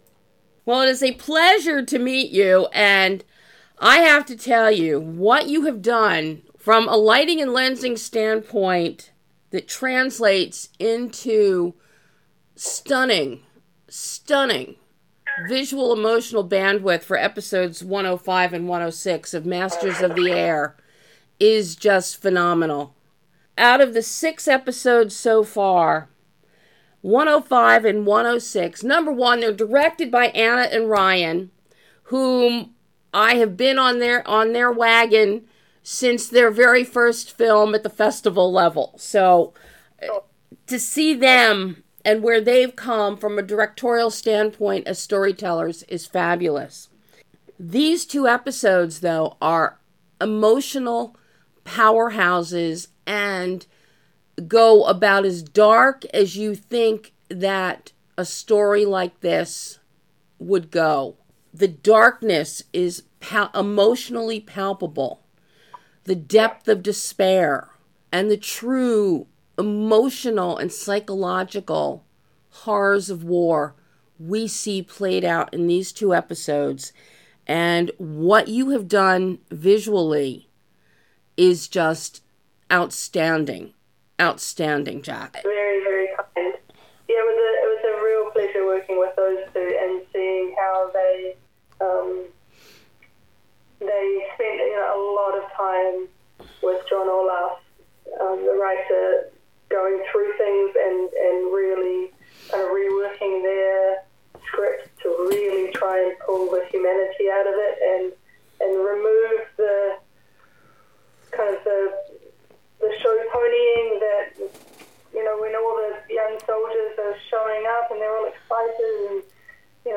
and finding the humanity within MASTERS OF THE AIR - Exclusive Interview